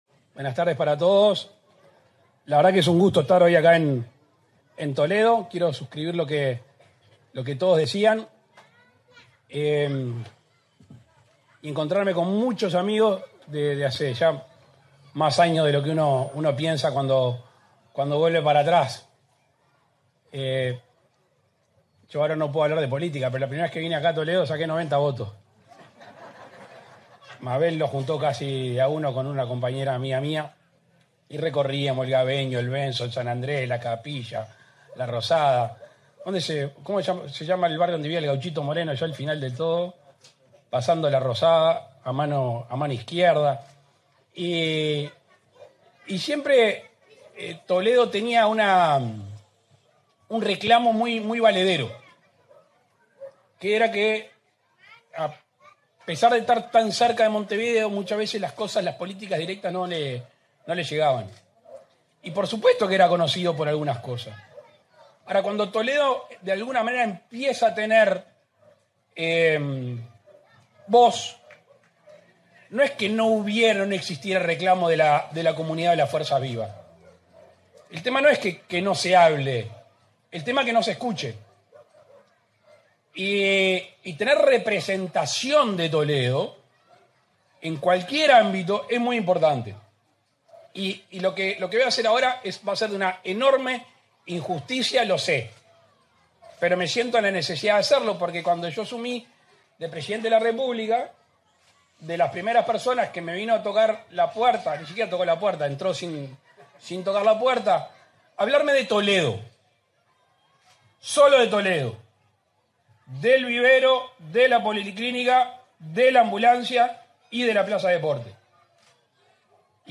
Palabras del presidente de la República, Luis Lacalle Pou
Palabras del presidente de la República, Luis Lacalle Pou 14/10/2024 Compartir Facebook X Copiar enlace WhatsApp LinkedIn En el marco de la inauguración de obras en la plaza de deportes en Toledo, este 14 de octubre, se expresó el presidente de la República, Luis Lacalle Pou.